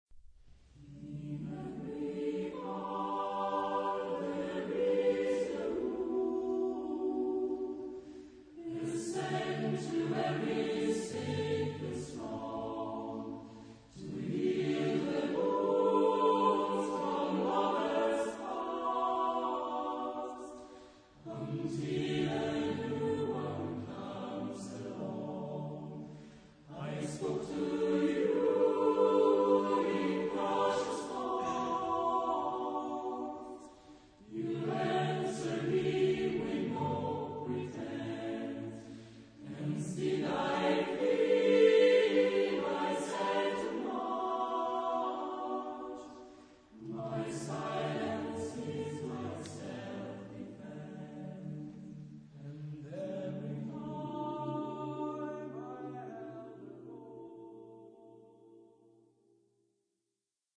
Balada.
Coral jazz.